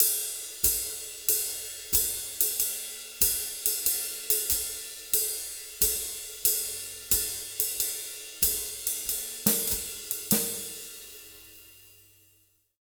92SWING 04-R.wav